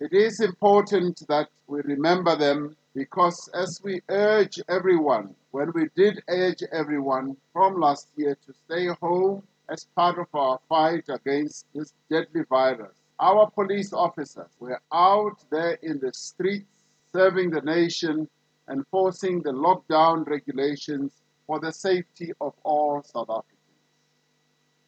During the annual South African Police Service Commemoration Day, Ramaphosa said more would also be done to bring to book those who are responsible for killing police officers.